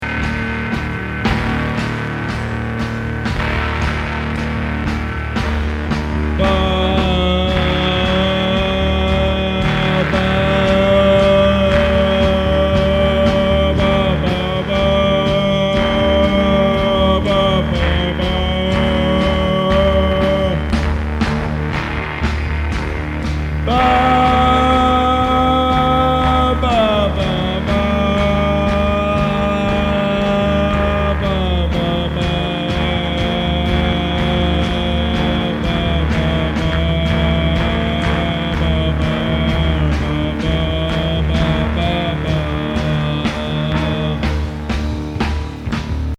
アルゼンチンの強烈極まりないアヴァン・サイケ・バンド。
する呪術サイケ・ドローン!